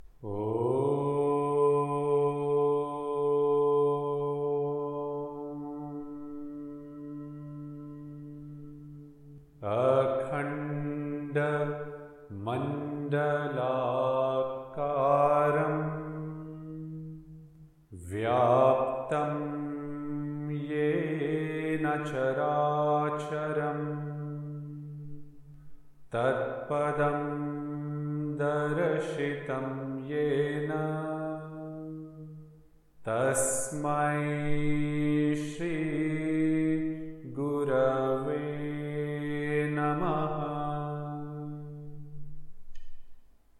Chanting